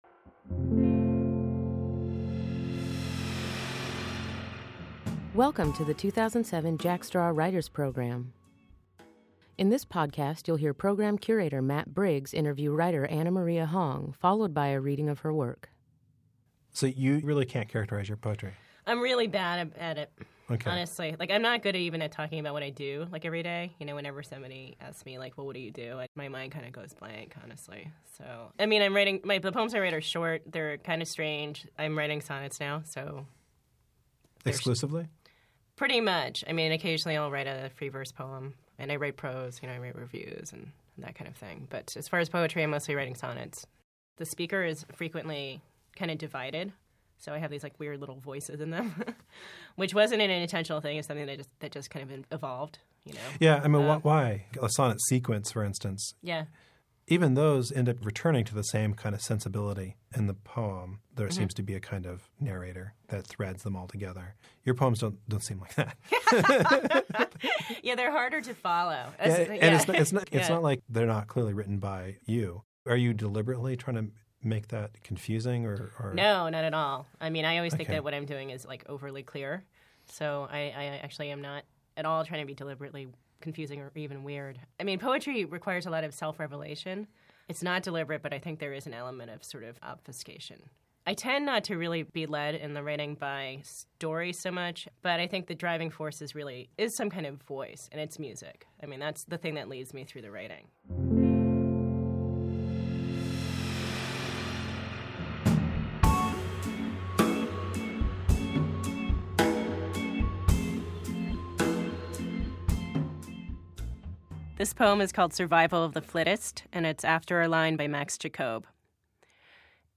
Reading Preview